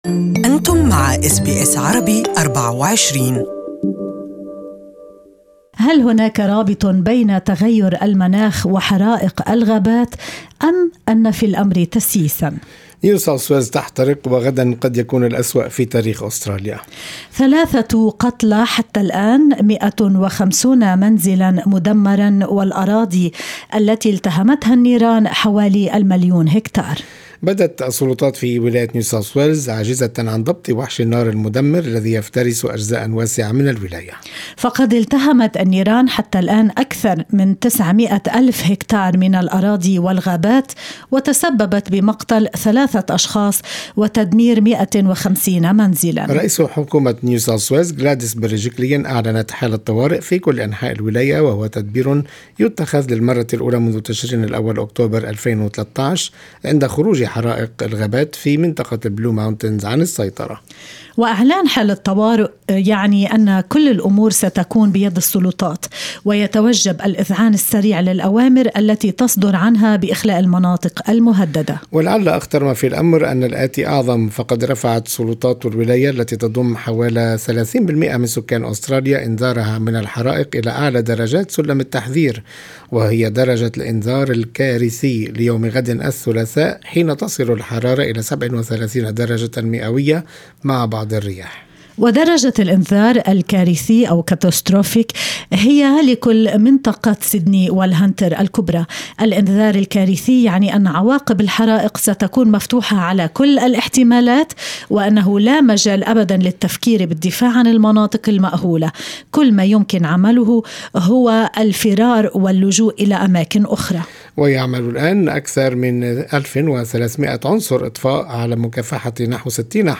يمكنكم الاستماع إلى اللقاء كاملاً